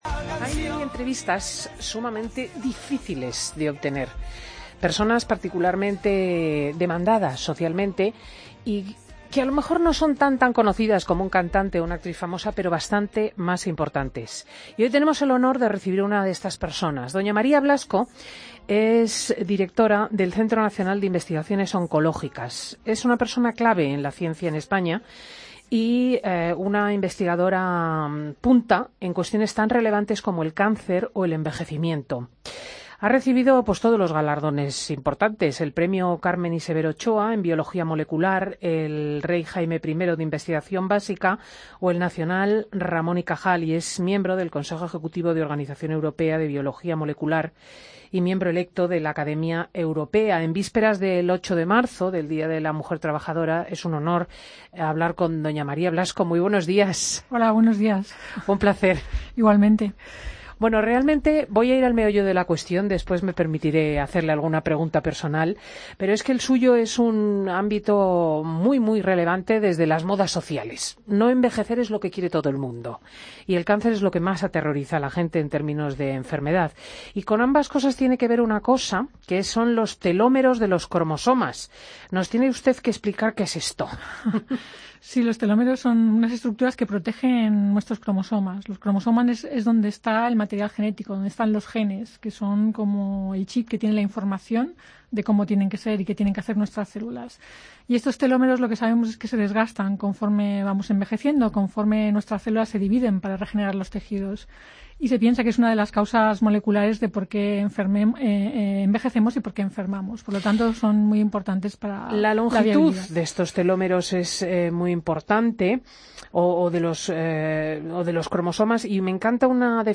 Escucha la entrevista a María Blasco, Directora del CNIO, en Fin de Semana COPE